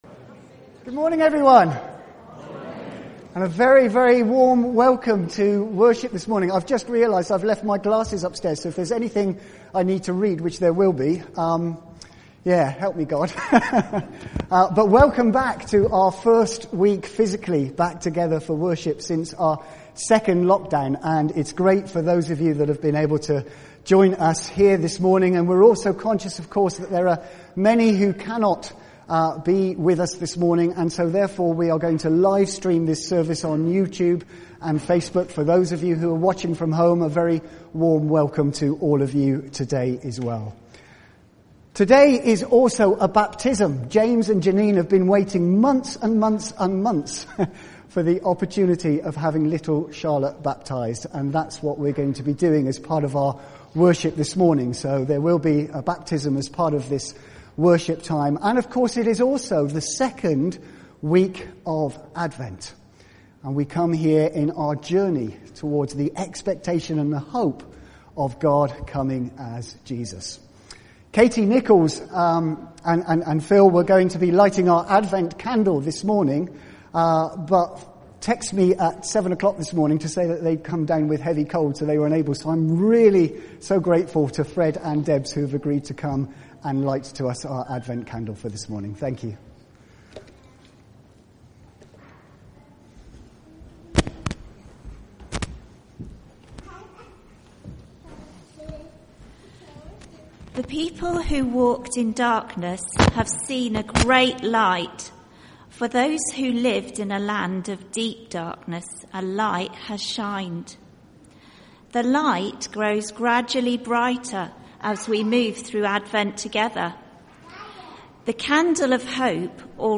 A message from the series
From Service: "10.30am Service"